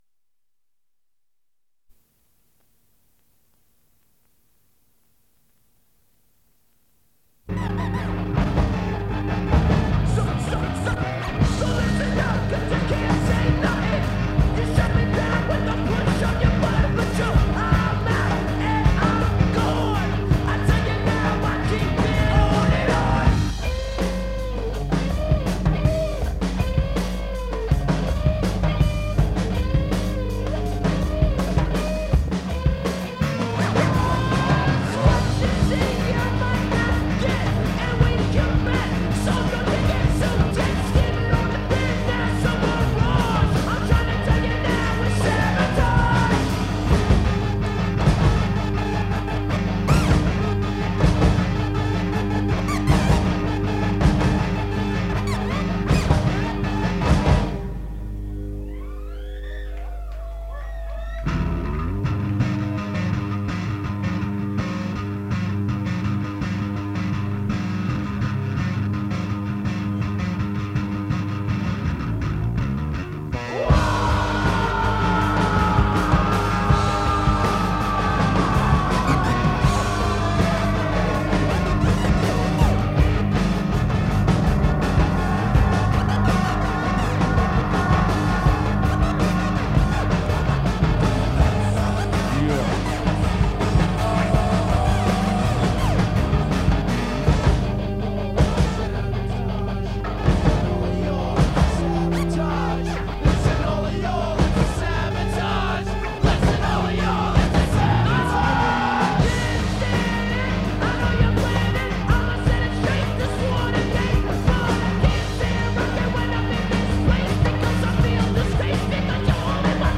Onderdeel van Live in Metropool Hengelo B